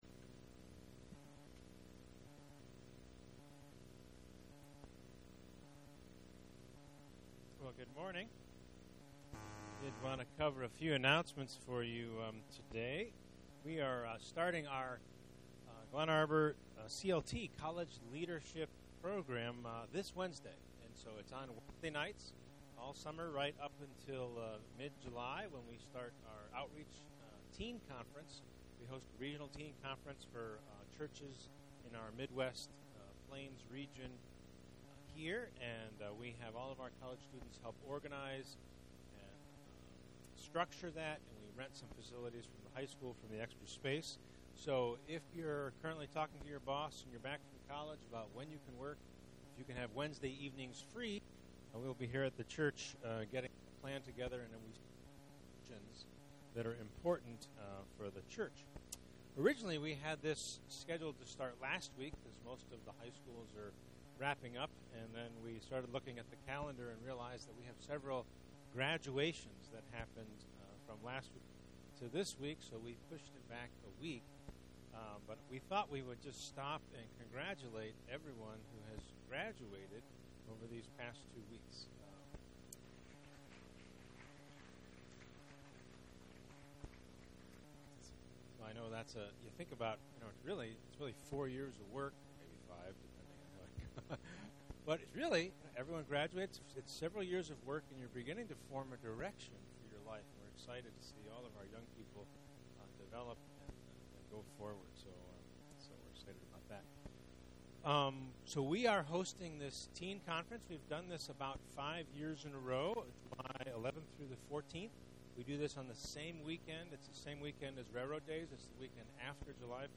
Faithfulness & Stewardship Service Type: Sunday Morning %todo_render% « God’s Money and My life